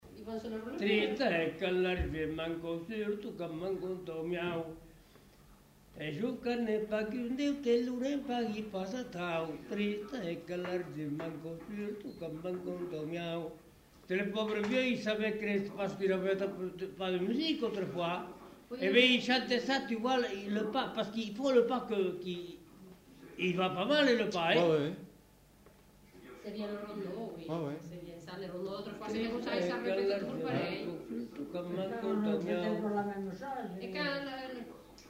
Lieu : Pavie
Genre : chant
Effectif : 1
Type de voix : voix d'homme
Production du son : chanté
Danse : rondeau